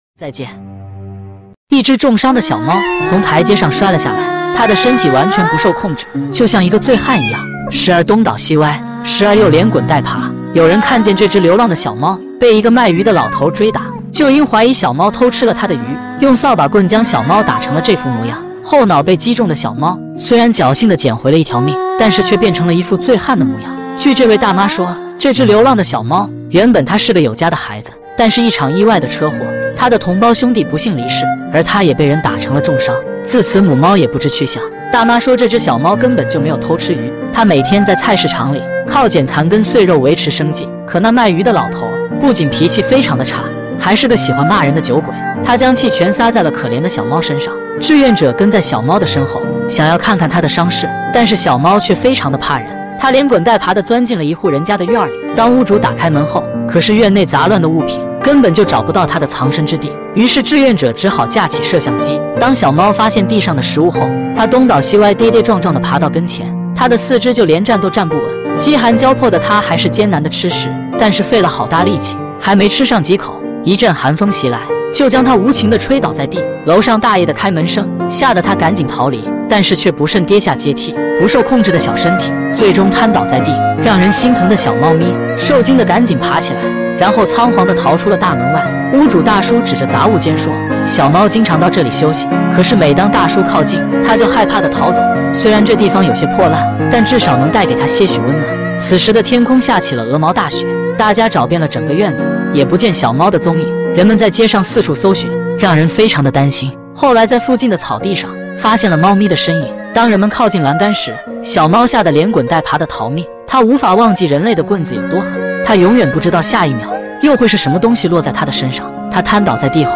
清澈解说 sound effects free download